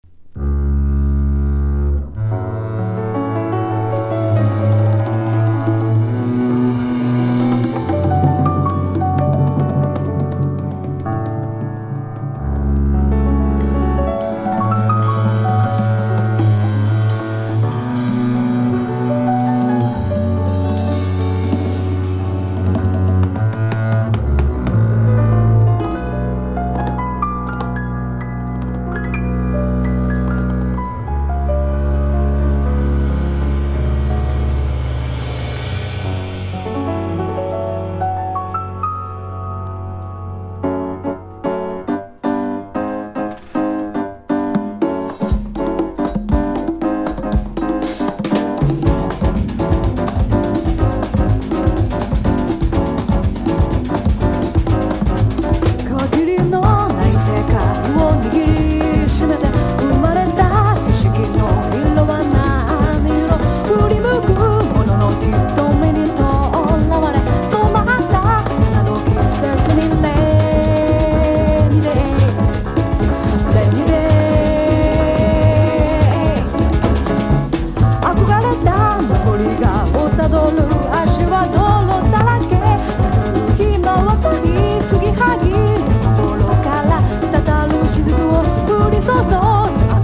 • JAZZ
POP